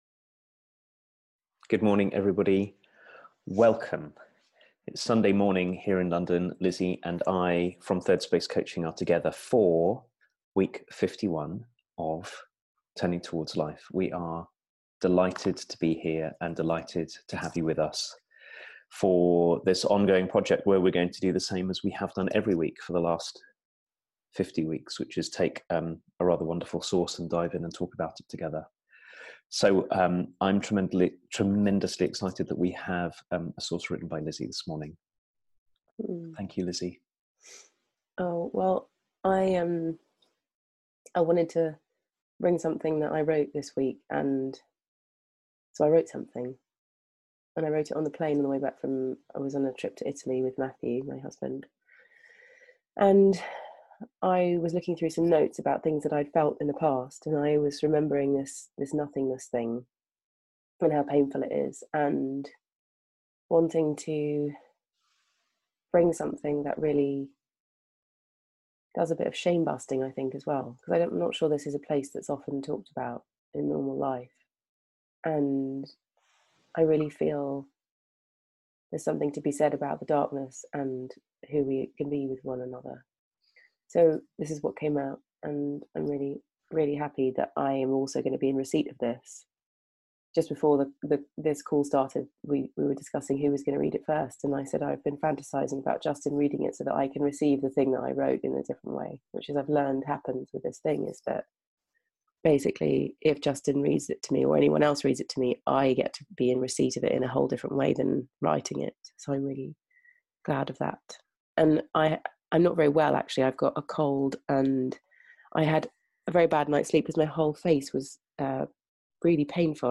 A conversation about openness